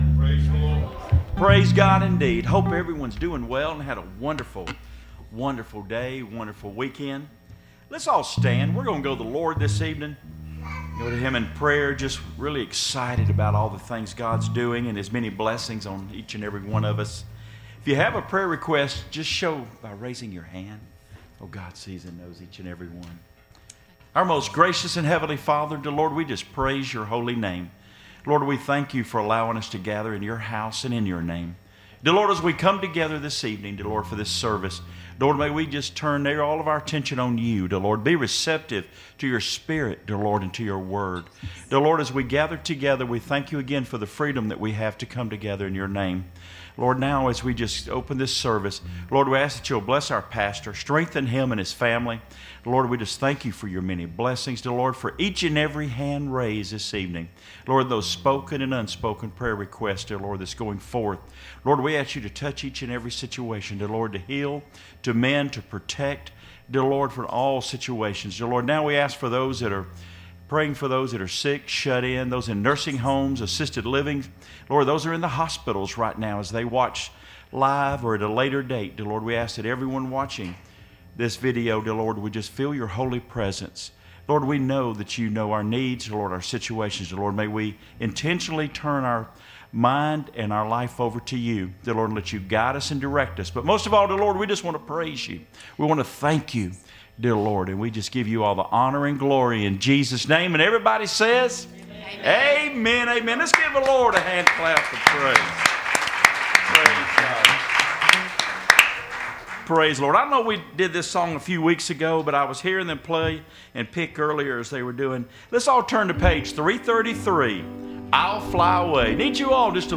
A Night of Singing